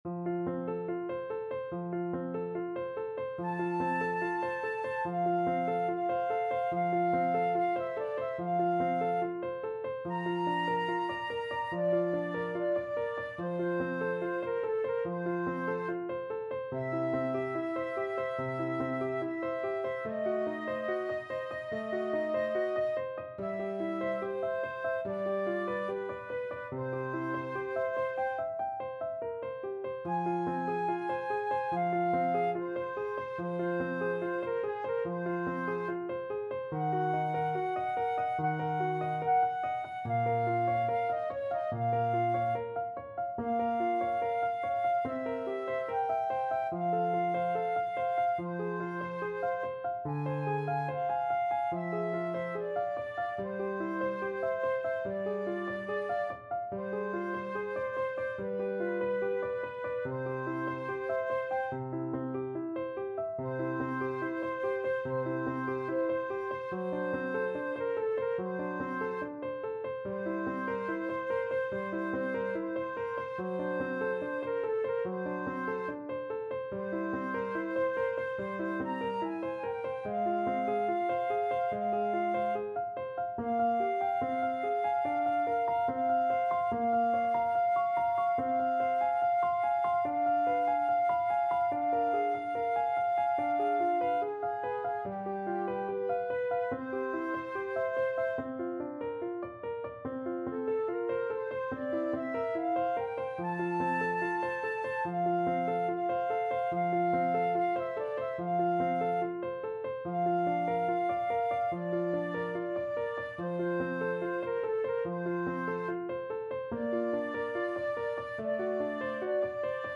Andante =72
Flute Duet  (View more Intermediate Flute Duet Music)
Classical (View more Classical Flute Duet Music)